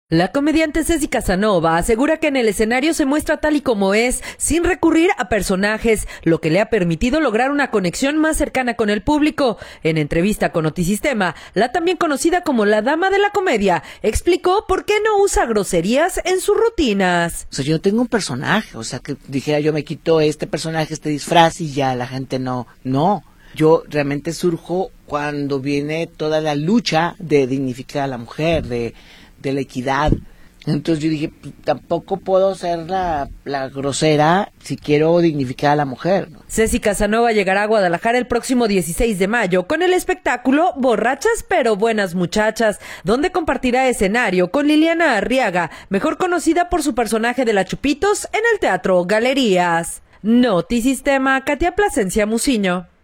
La comediante Cessy Casanova asegura que en el escenario se muestra tal cual es, sin recurrir a personajes, lo que le ha permitido lograr una conexión más cercana con el público. En entrevista con Notisistema, la también conocida como “La dama de la comedia” explicó por qué no usa groserías en sus rutinas.